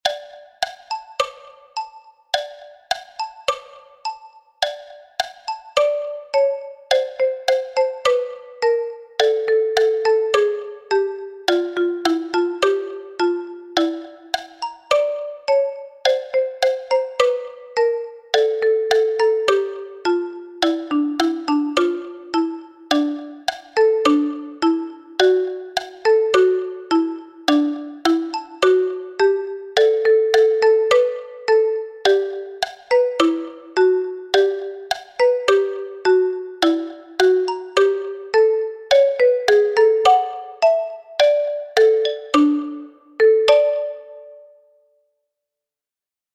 Marimba
Habanera_Carmen_Bizet_-_MARIMBA.mp3